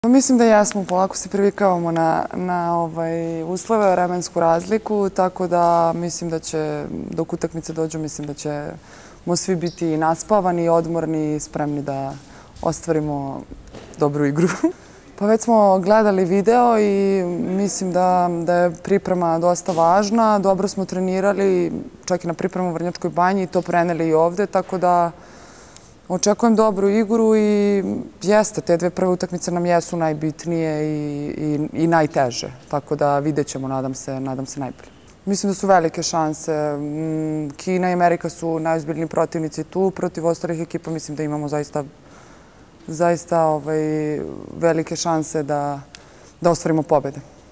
IZJAVA BOJANE ŽIVKOVIĆ